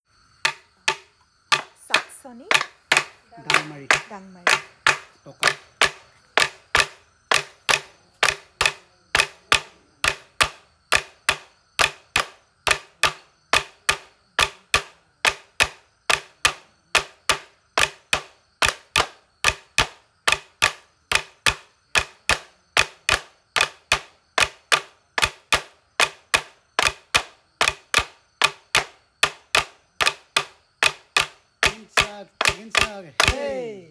Saksoni creates melody, sound waves by beating on Dangmari.
Men playing Saksoni & Dangmari